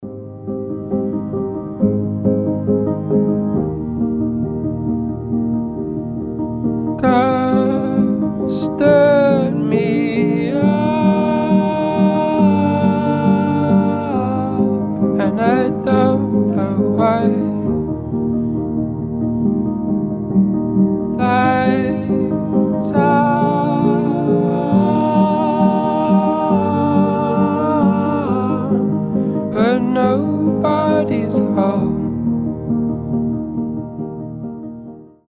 грустные
пианино
indie pop
фолк
печальные